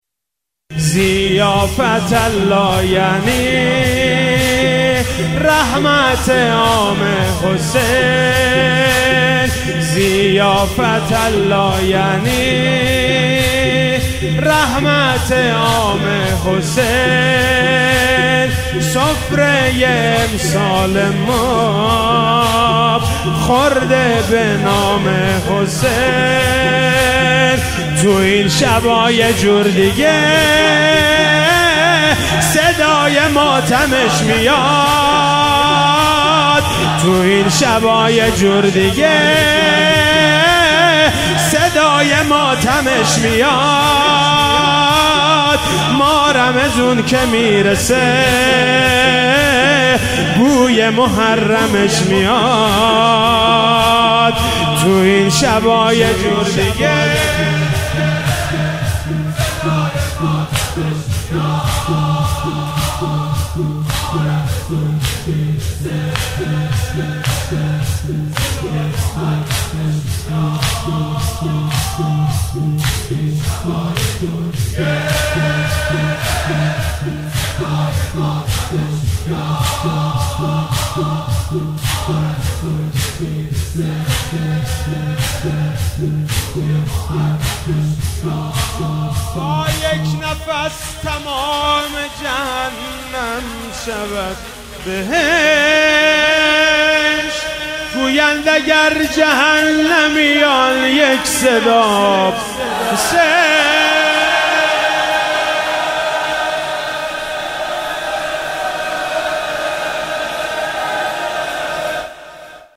شب نوزدهم ماه رمضان98 - زمینه - ضیافت الله یعنی